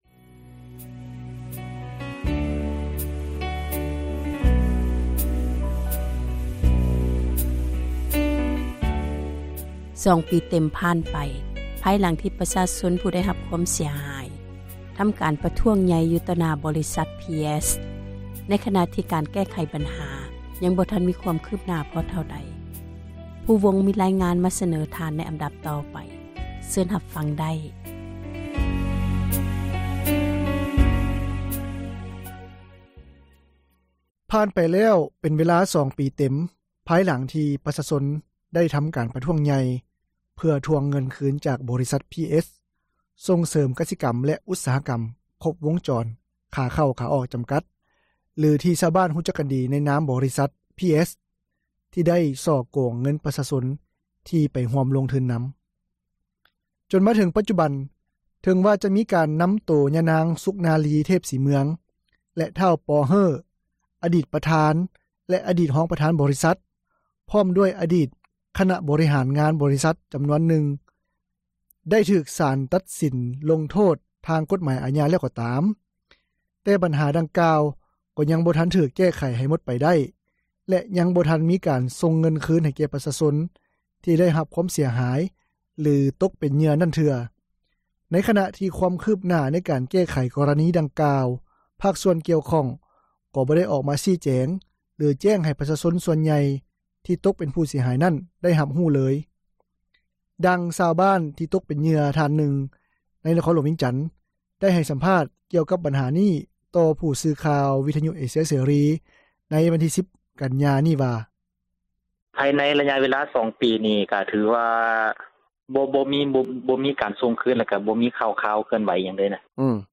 “ສຽງຊາວບ້ານທີ່ແຂວງບໍ່ແກ້ວນາງນຶ່ງ ທີ່ຕົກເປັນເຫຍື່ອ”
“ສຽງຜູ້ທີ່ສັງເກດກໍຣະນີສໍ້ໂກງບໍຣິສັດ PS”